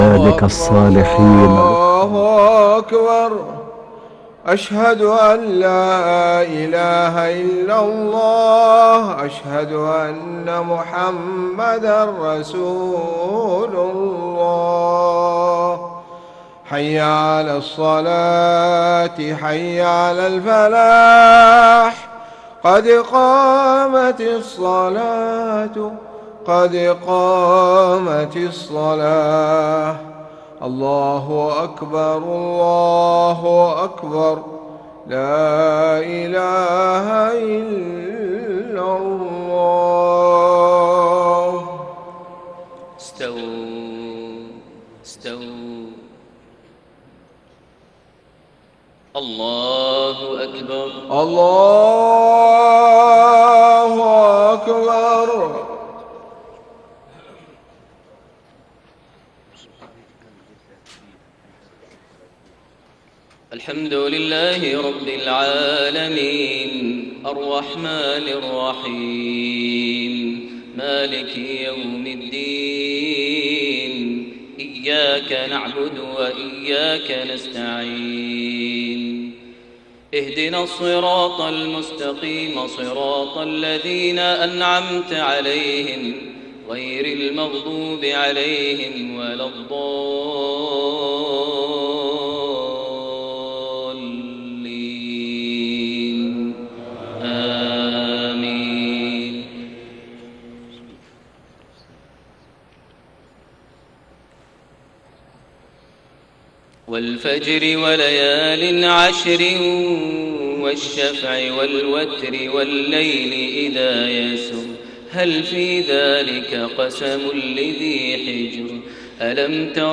صلاة المغرب 13 ذو القعدة 1432هـ سورة الفجر > 1432 هـ > الفروض - تلاوات ماهر المعيقلي